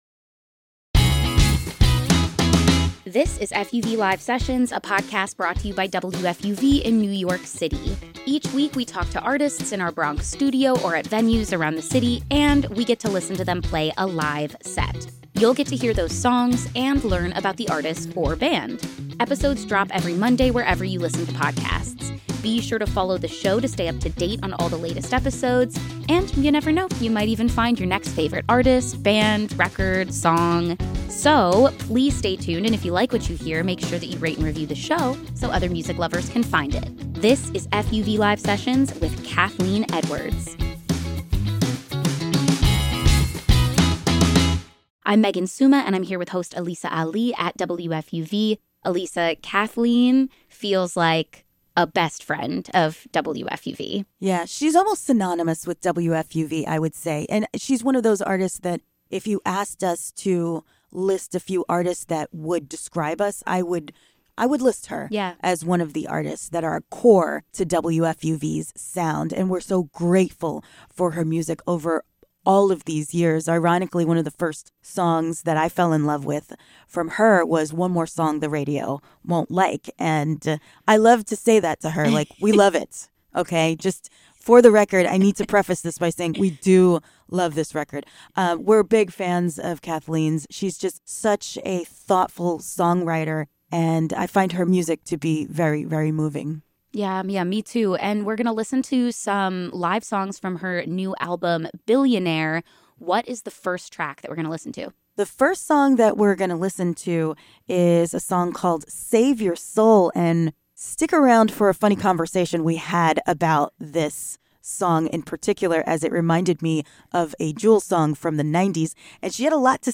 Singer, songwriter, musician
in Studio-A